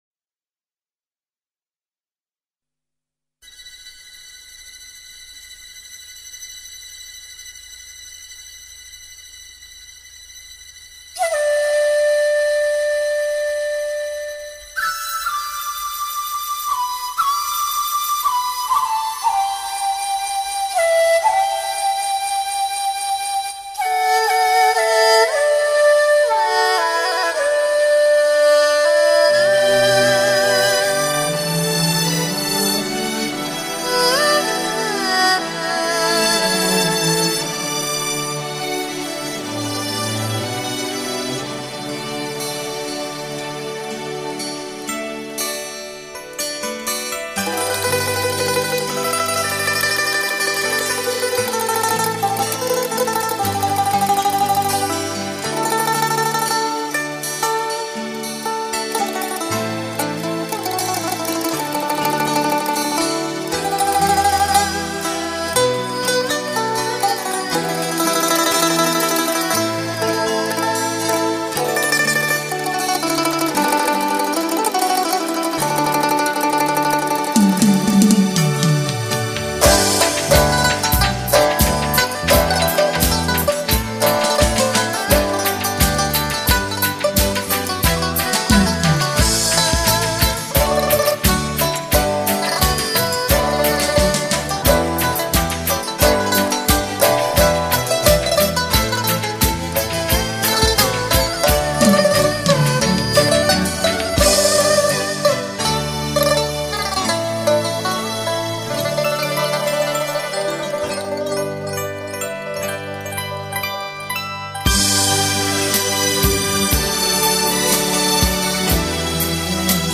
汇集中国最具代表性的乐器演奏名家名曲最高水平的代表。
琵琶